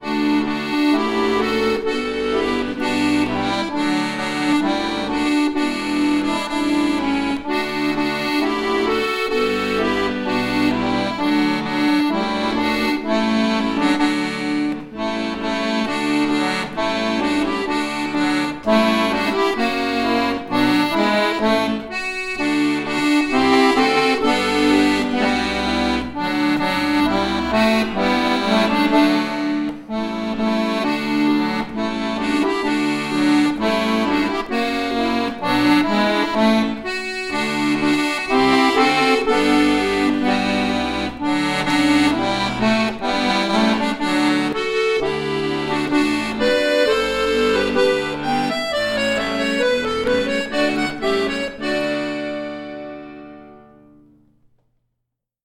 in einer gekürzten Fassung für Akkordeon solo
Klassisch